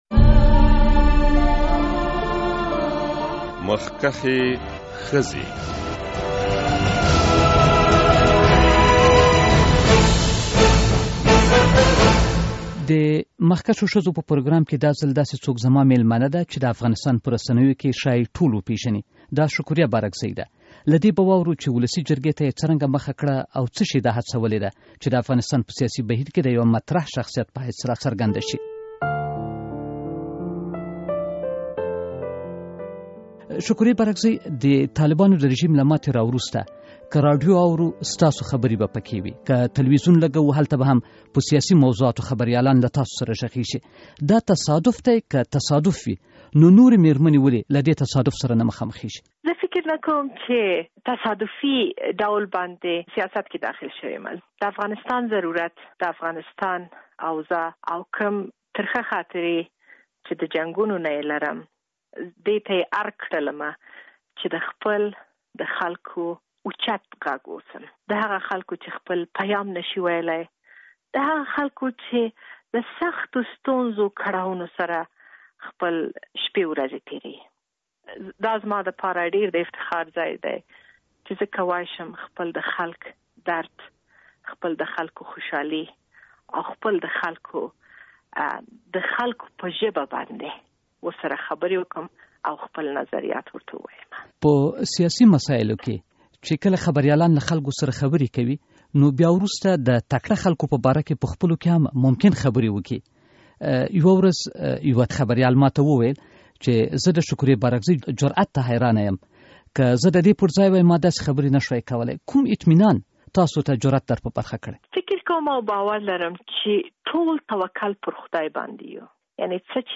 په اوسني سیاسي بهیر کې له یوې مخکښې میرمن سره مرکه